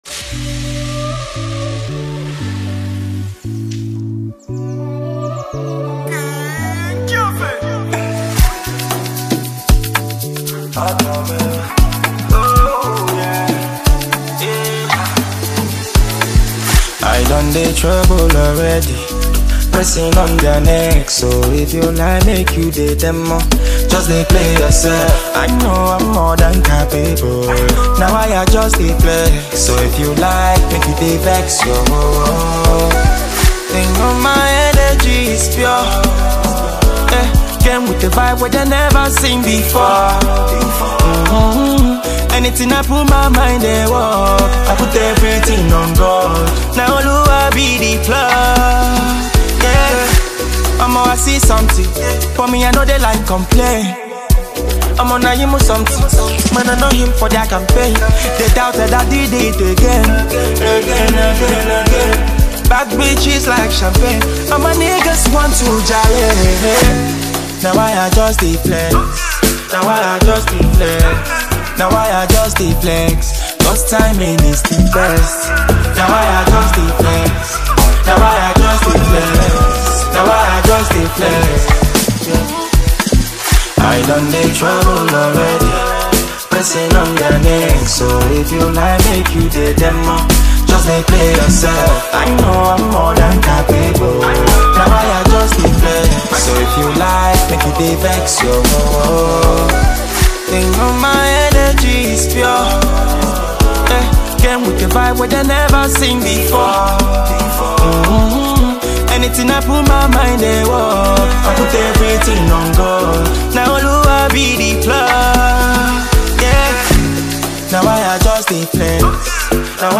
Well renowned Nigerian artist and performer
thrilling new gbedu song